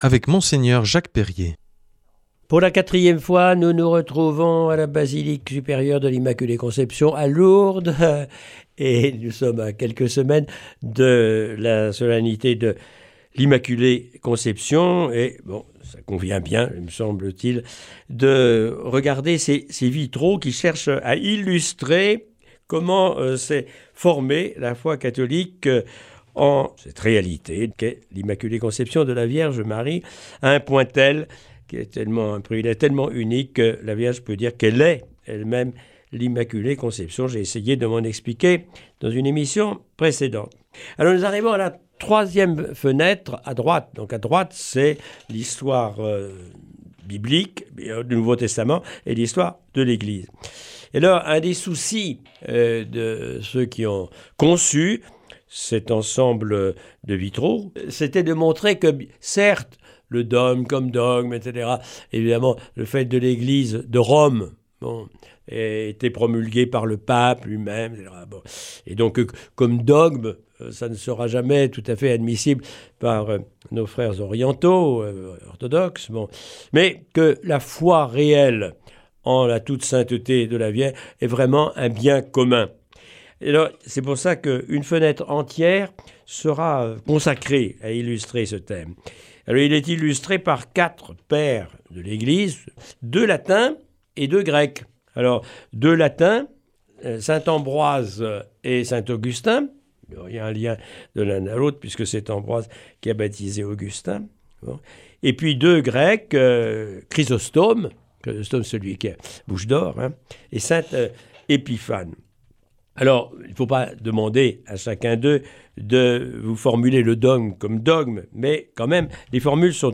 jeudi 21 novembre 2024 Enseignement Marial Durée 10 min